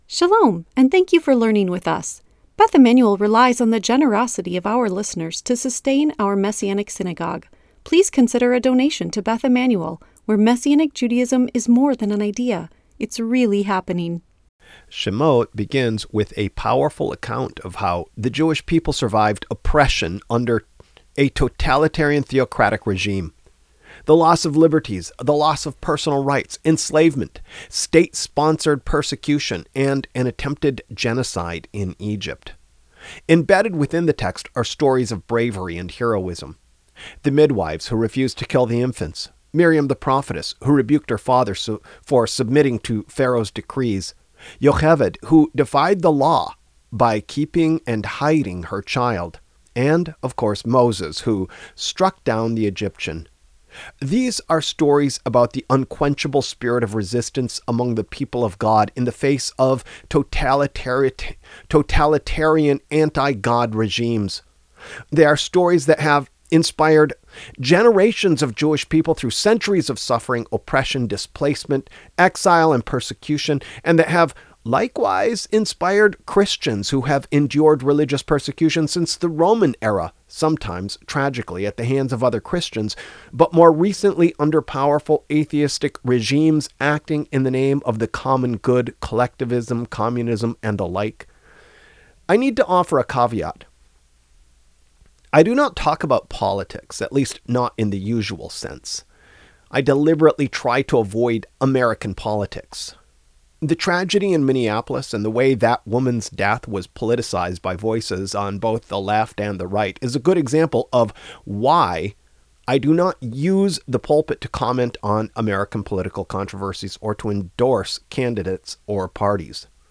A teaching based on Rod Dreher's books The Benedict Option and Live Not By Lies.